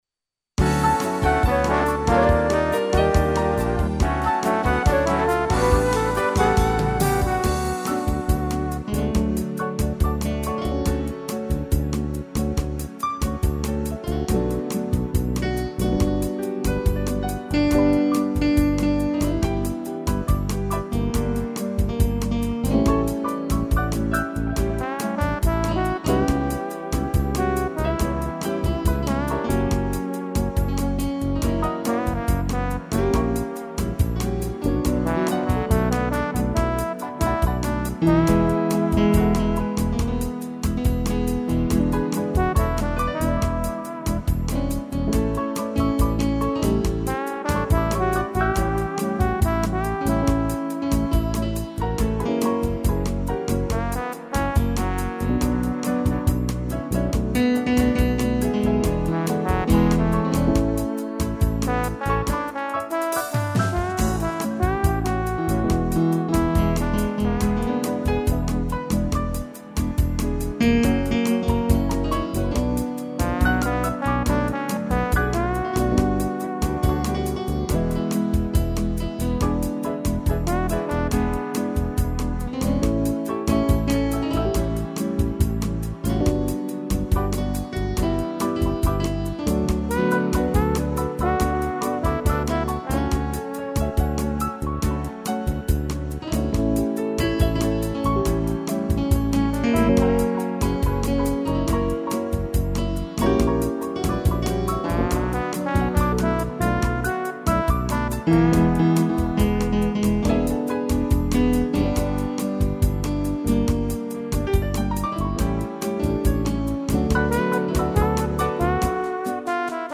piano e trombone
instrumental